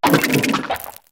nacli_ambient.ogg